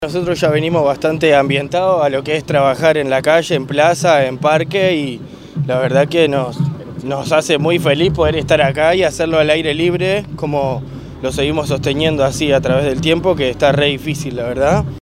Fiesta de la Cerveza Artesanal en Parque del Plata
artista_circense_-_fiesta_cerveza.mp3